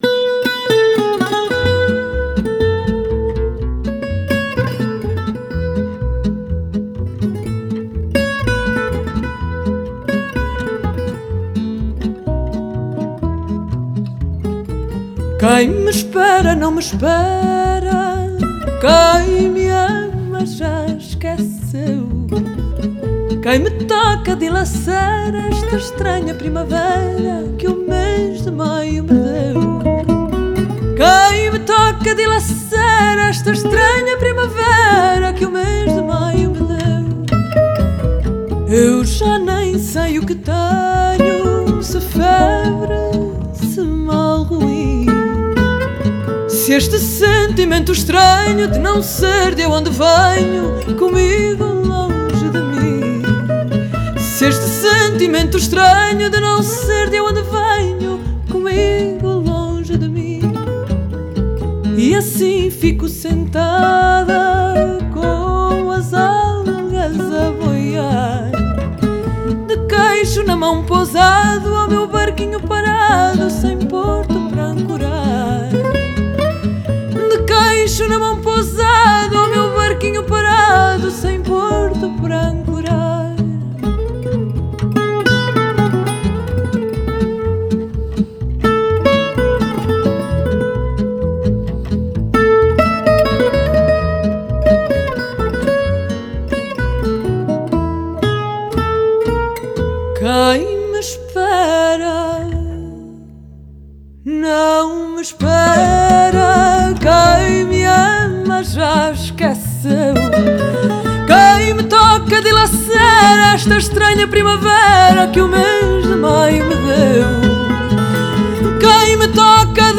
Genre: Folk, World, & Country
Style: Fado